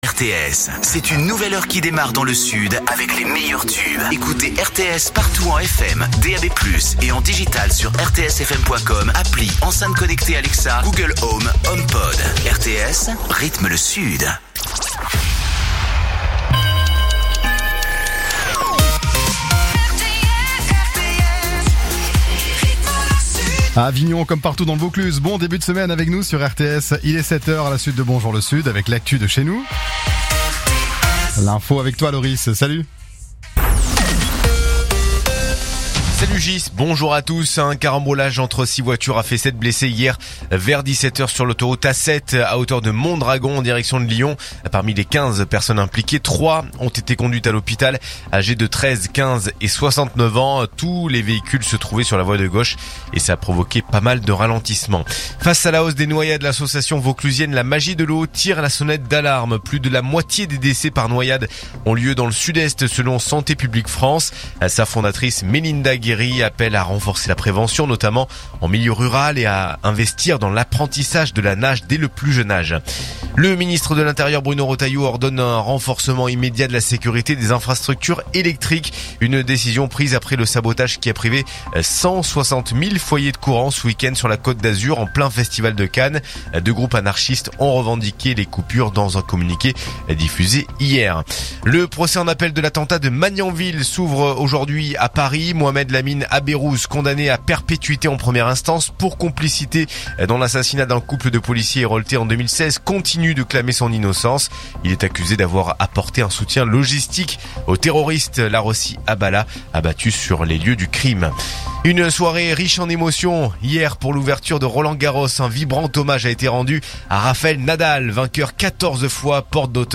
info_avignon_395.mp3